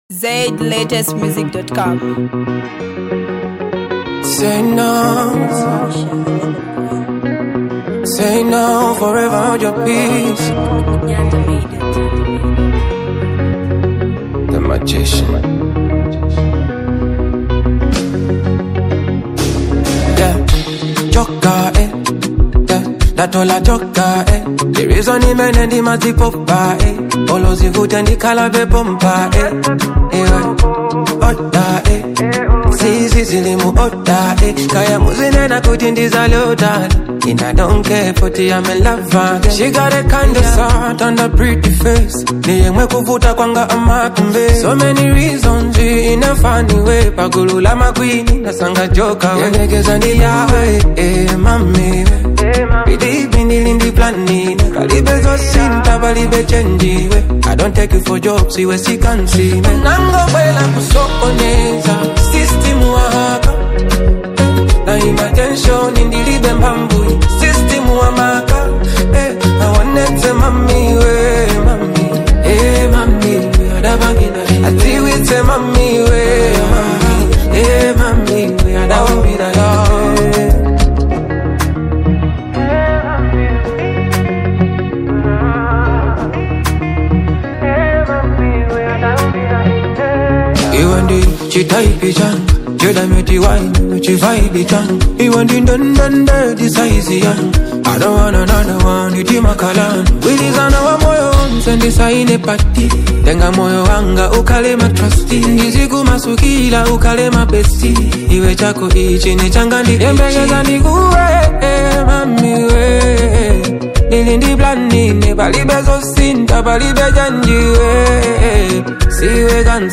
catchy yet soothing rhythm